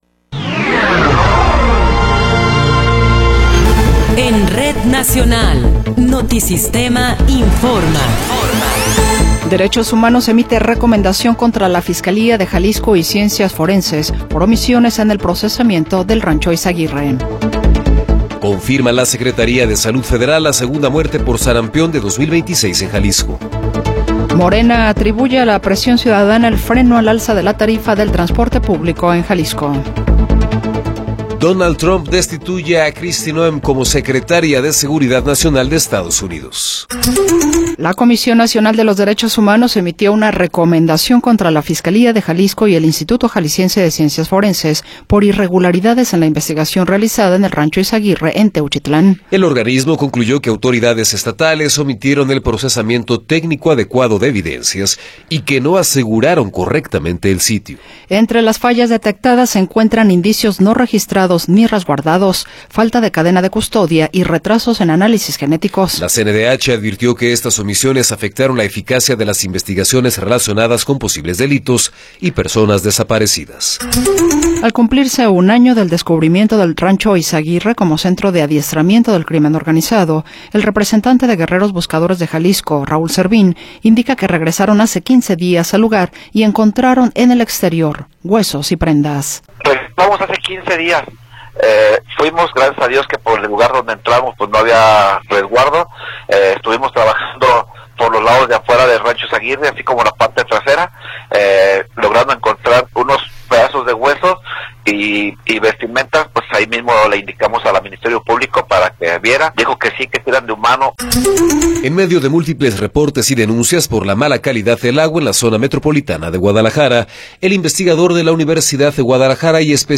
Noticiero 14 hrs. – 5 de Marzo de 2026
Resumen informativo Notisistema, la mejor y más completa información cada hora en la hora.